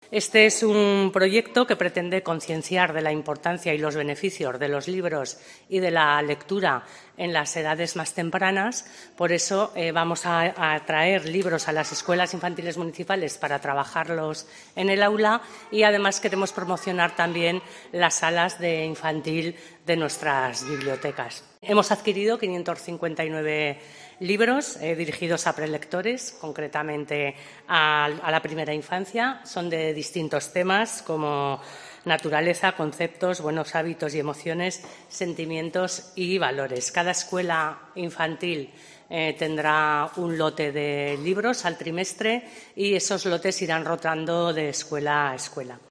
Las concejala de Educación, Paloma Espinosa, explica el objetivo del programa 'Pequelecturas'